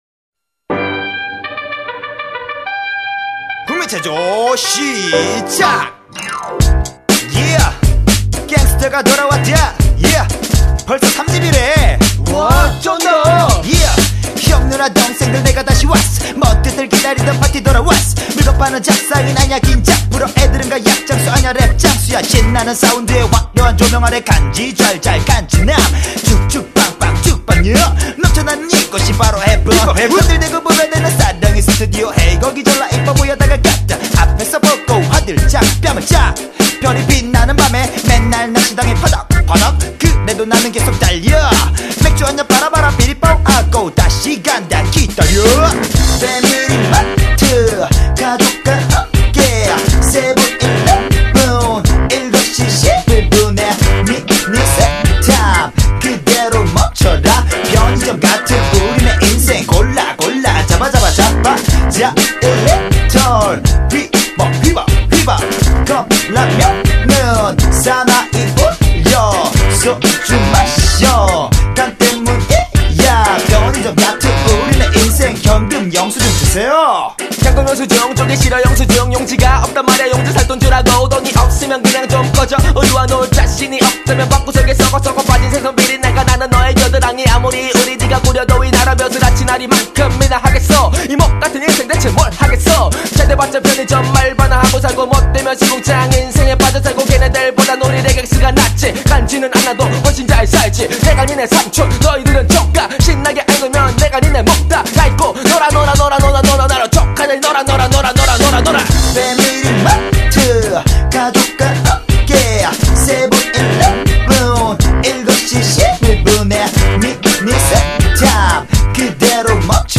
• [REMIX.]
셋이서 신나게 즐기면서 녹음해봤어요.